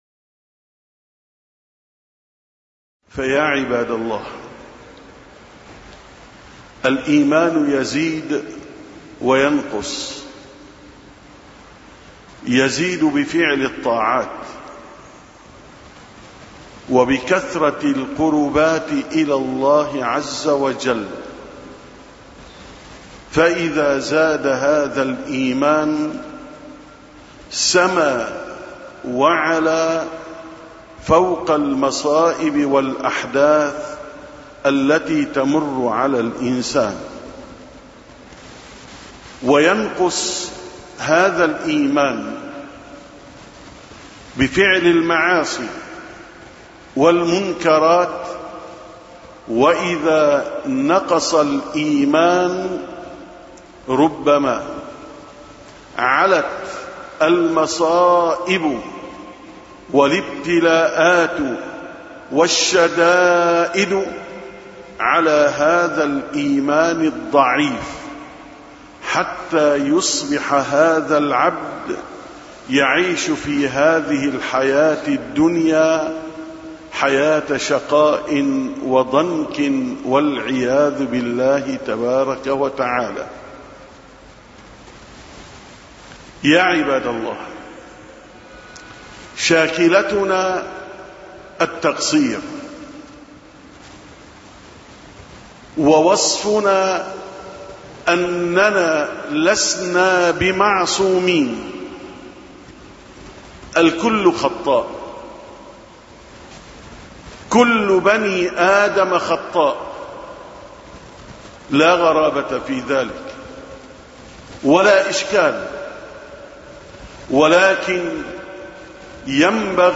880ـ خطبة الجمعة: الحذر من الإصرار على المعصية